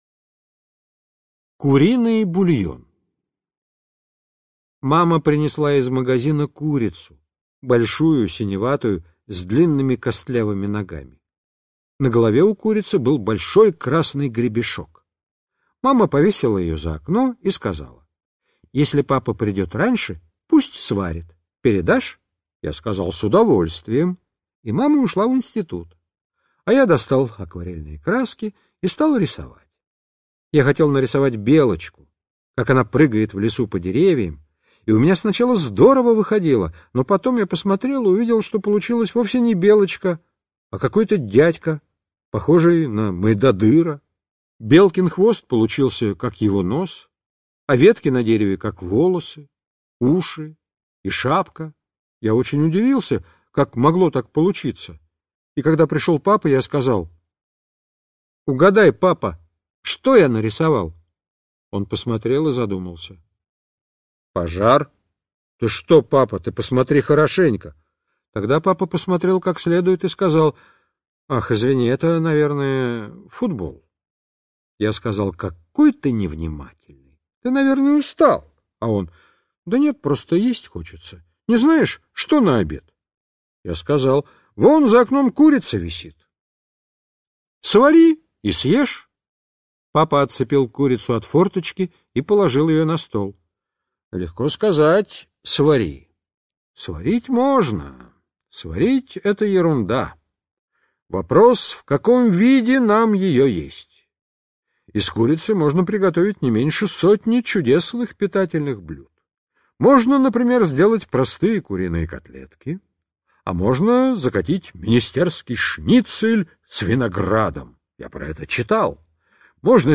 Куриный бульон - аудио рассказ Драгунского В.Ю. Рассказ о том, как Дениска с папой пытались сварить курицу.